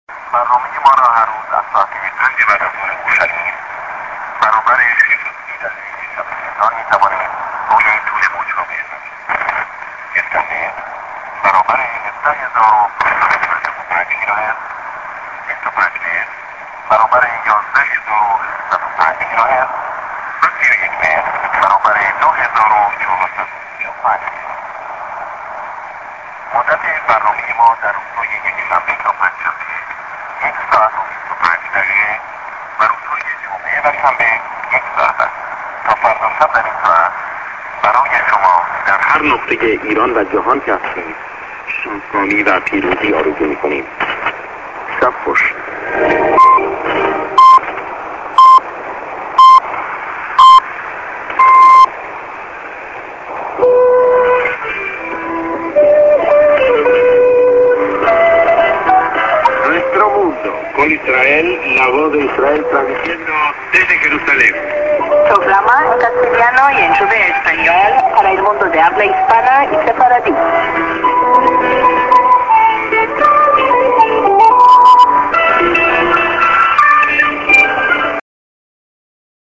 St. SKJ(man)->TS->SJ->ID(man+women)->